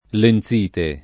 [ len Z& te ]